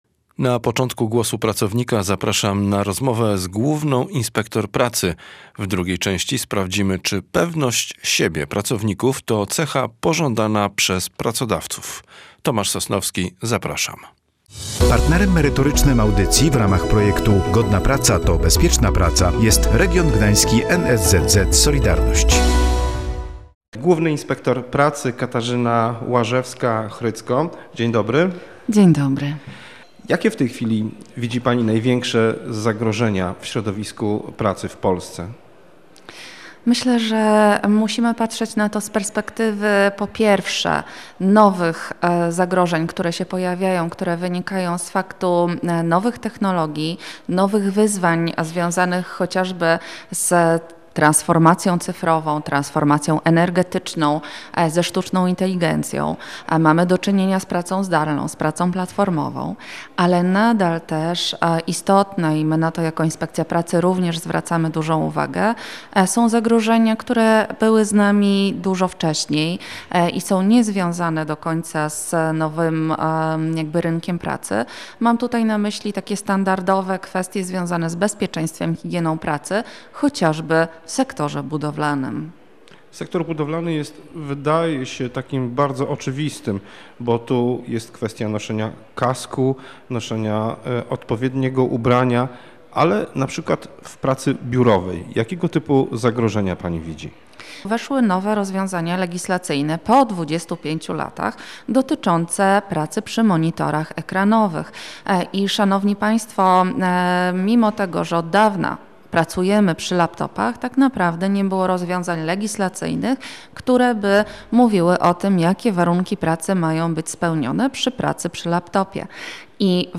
Są nowe przepisy dotyczące pracy biurowej. Zmiany przybliża Główna Inspektor Pracy